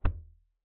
Play grunk collection noises based on modeled decay
collect2.wav